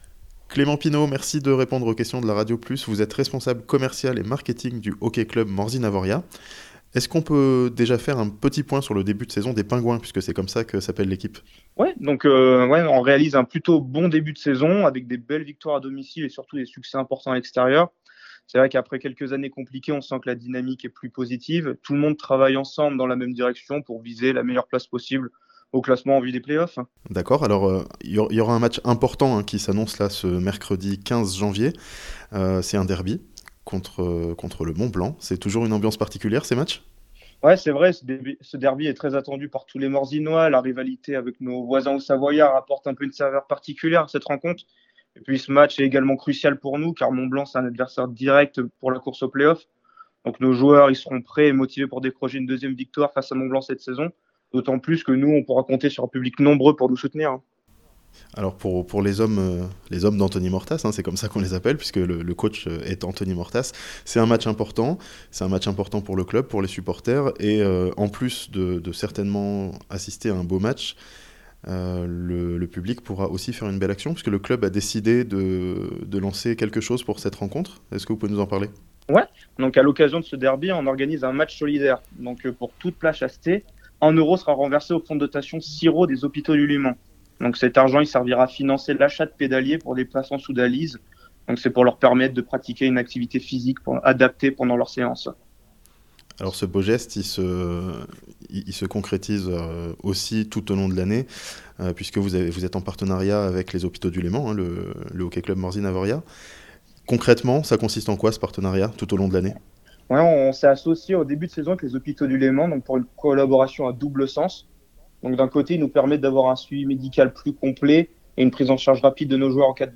Les hockeyeurs morzinois joueront un derby solidaire au profit des Hôpitaux du Léman (interview)